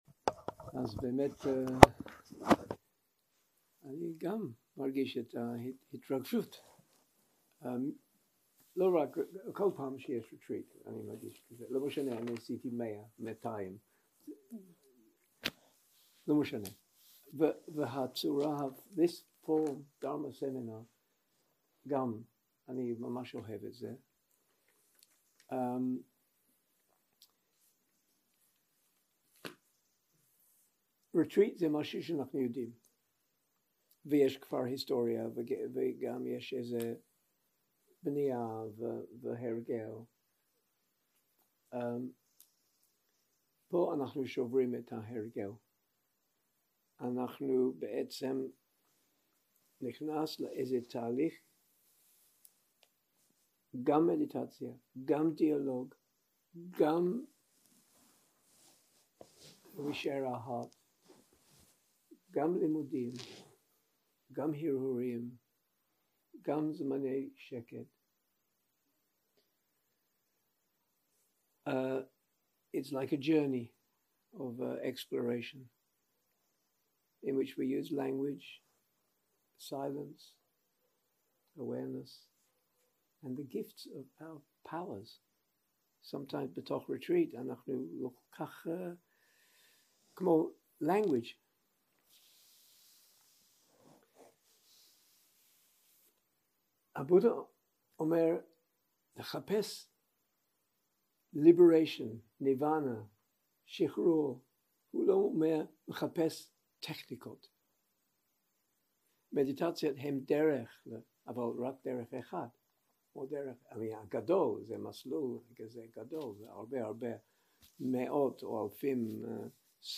Dharma type: Opening talk